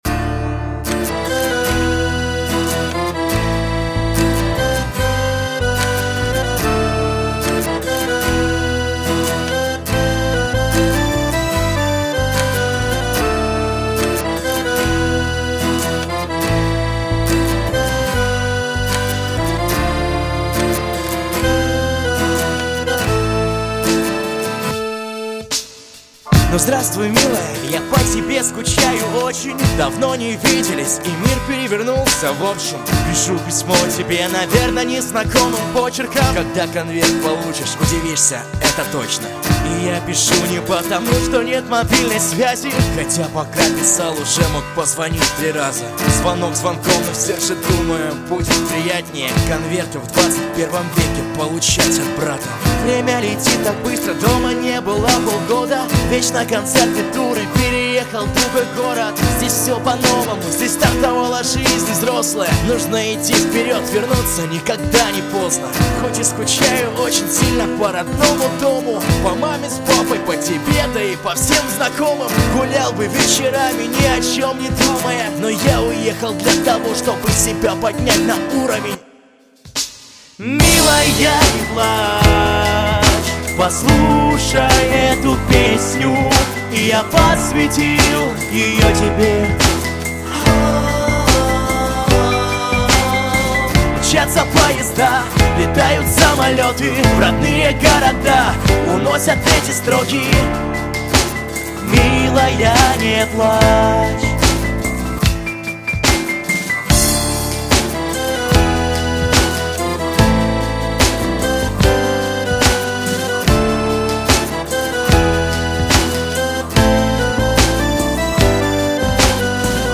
Мужской
Тенор Бас